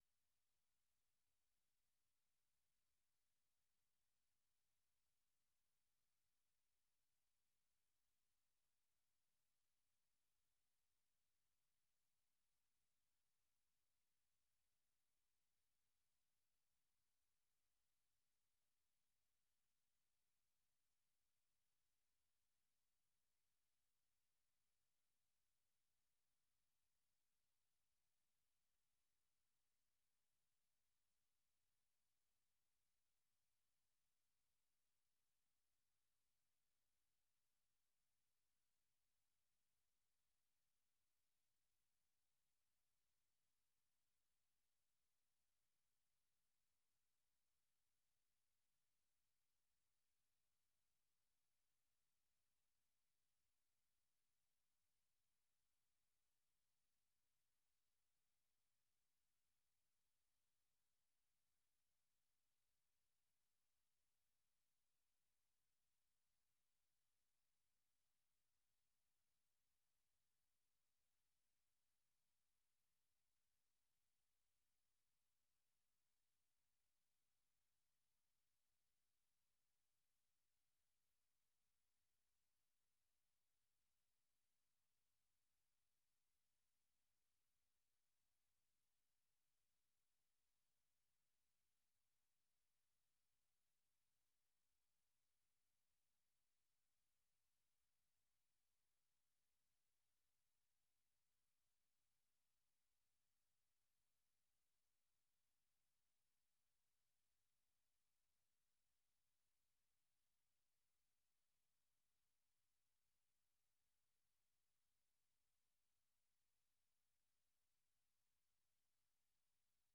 د ورځې په خبرونو اومسایلو د نظر د خاوندانو سپینې خبرې او د اوریدونکو نظرونه